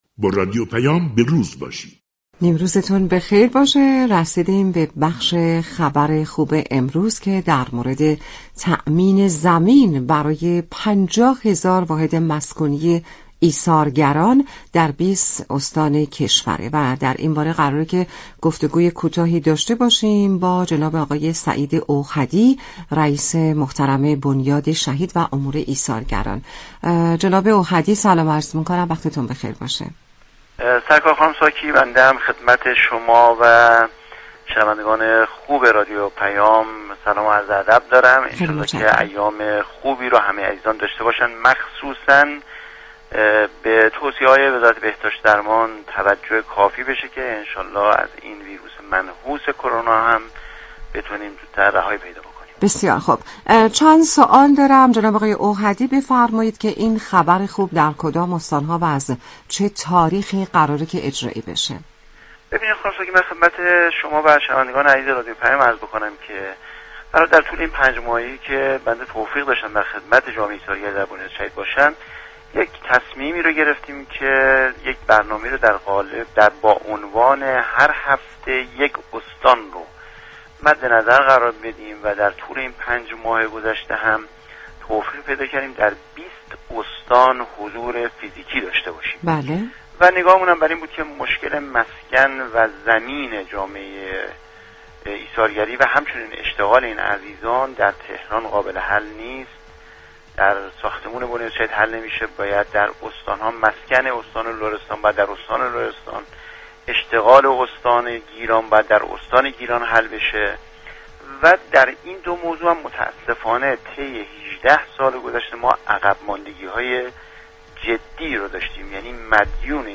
اوحدی، معاون رئیس جمهور و رئیس بنیاد شهید و امور ایثارگران، در گفتگو با رادیو پیام ، از تأمین زمین برای 50 هزار واحد مسكونی ایثارگران در 20 استان كشور خبر داد .